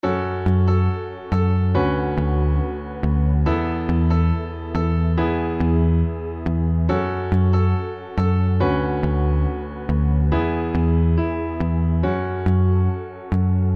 SFX短视频背景转场音效下载
SFX音效